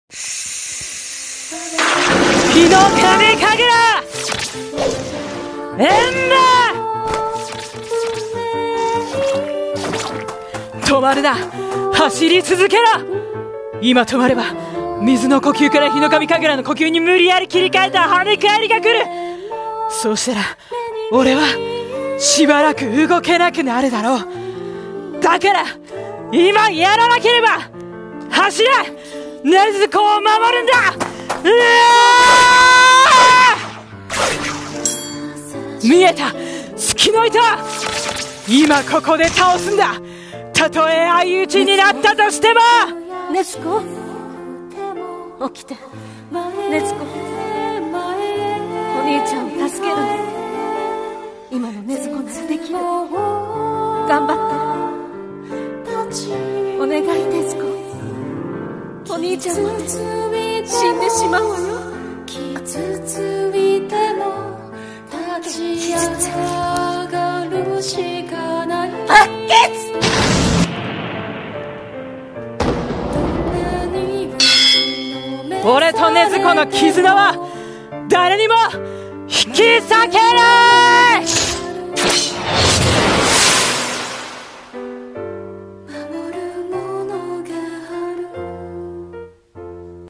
声劇】鬼滅の刃 第19話 ヒノカミ神楽×血鬼術 爆血 【掛け合い